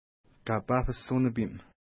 Pronunciation: ka:pa:hpəs unəpi:m
Pronunciation